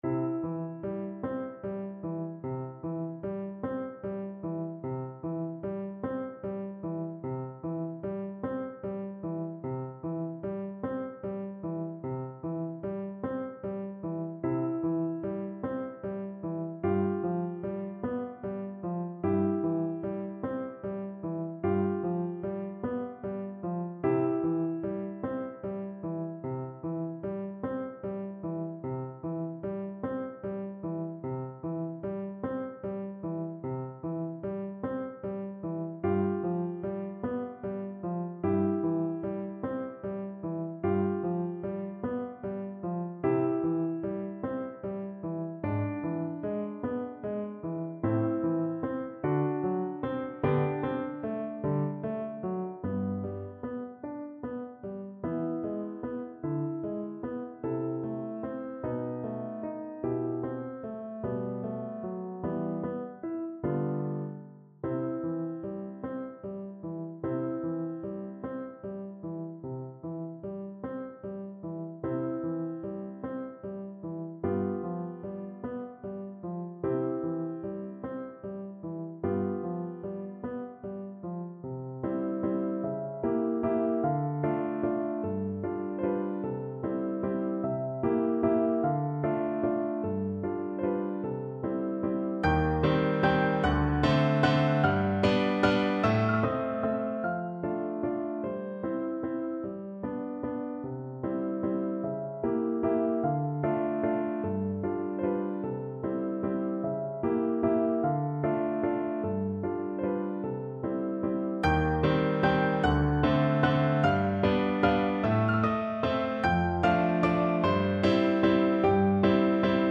Largo
Classical (View more Classical French Horn Music)